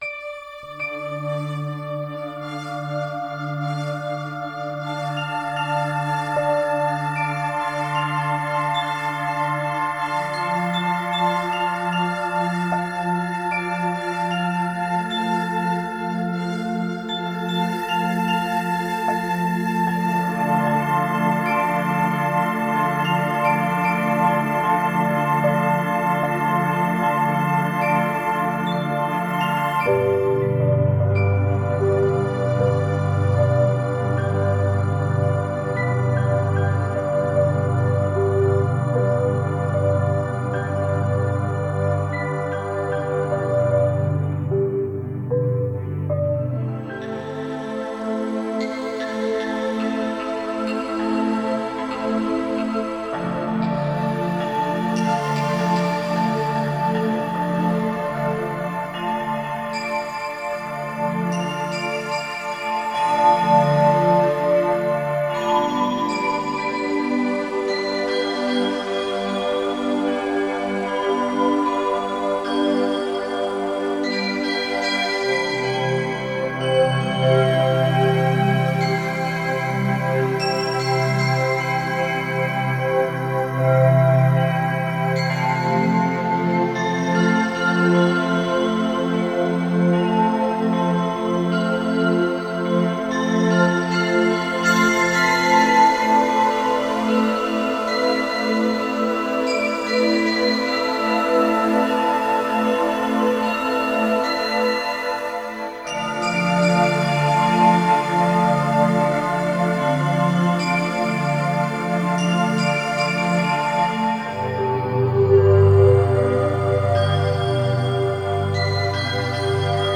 Медитативная музыка Мистическая музыка Духовная музыка